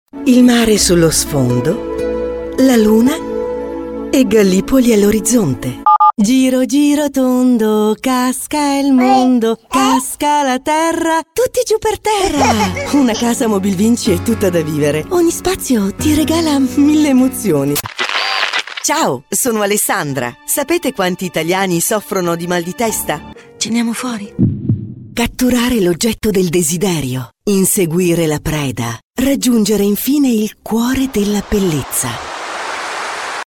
have been working at my professional home studio for 13 years, I record everything and have a flexible voice.
Sprechprobe: Werbung (Muttersprache):
commercials.mp3